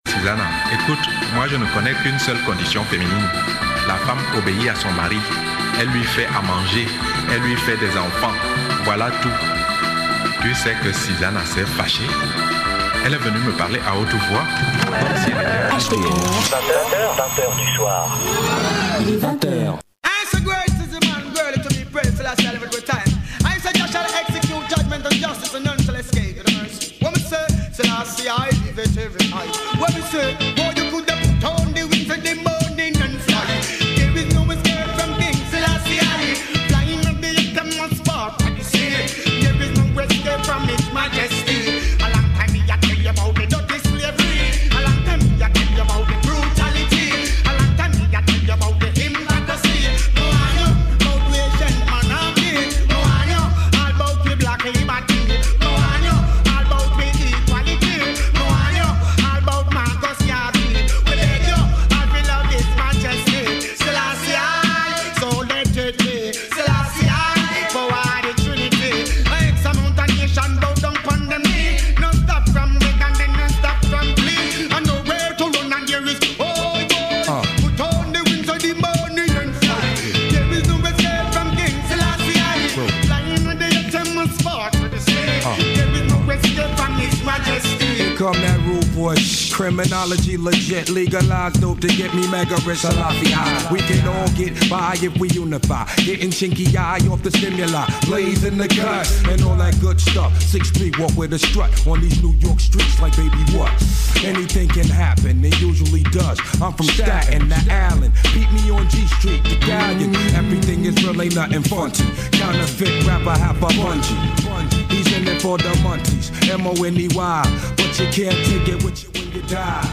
grosses sélections Roots et Dub inna Sound System Style